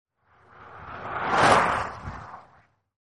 carPass1.mp3